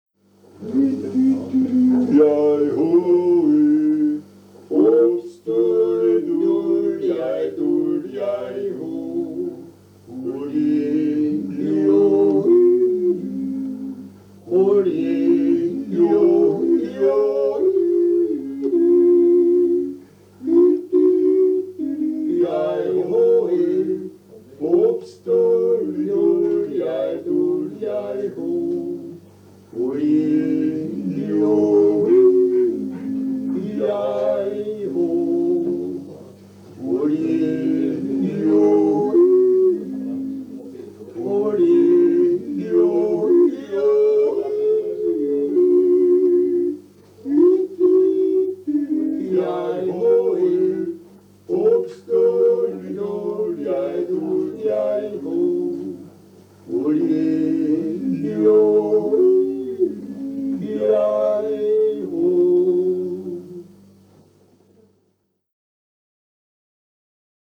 Jodler und Jodler-Lied – Ungeradtaktig
Yodel, Yodel-Song – triple metre (3/4): Lower Austrian and Styrian Wechsel-region; social structure; local dialect
Folk & traditional music